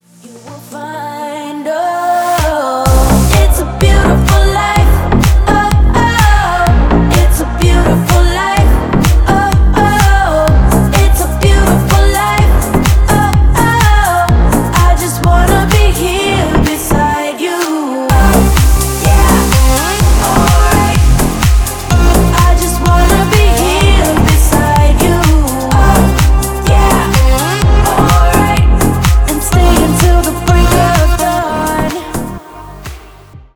• Качество: 320, Stereo
remix
retromix
клубные